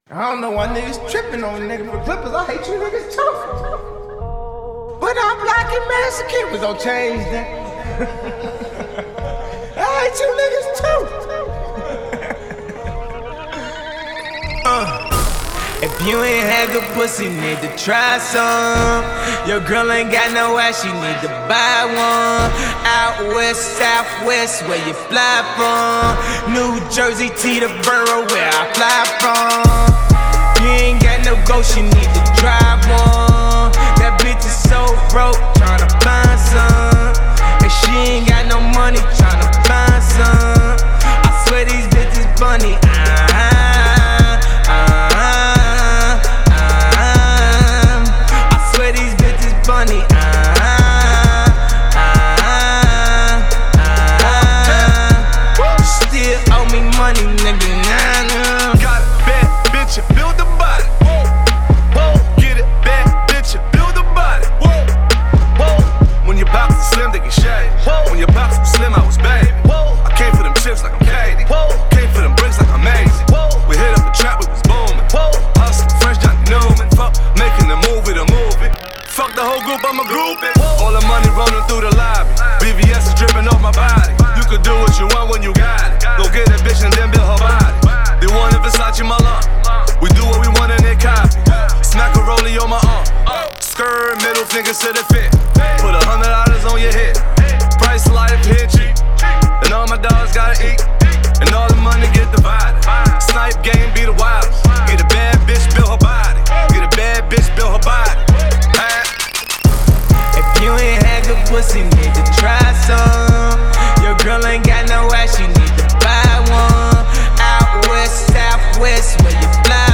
· by · in Hip Hop.
a studio cut